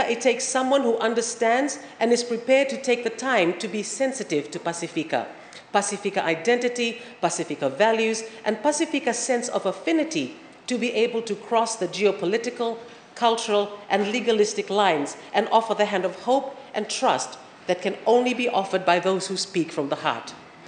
This was a statement made by the Assistant Minister for Foreign Affairs, Lenora Qereqeretabua, in parliament while responding to the 2023–24 budget debate.